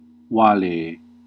Ääntäminen
Ääntäminen Classical: IPA: /ˈwa.leː/ Haettu sana löytyi näillä lähdekielillä: latina Käännös Konteksti Ääninäyte Huudahdukset 1. goodbye US Substantiivit 2. sweet dreams idiomaattinen Luokat Huudahdukset Verbit